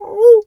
pgs/Assets/Audio/Animal_Impersonations/wolf_2_howl_soft_03.wav
wolf_2_howl_soft_03.wav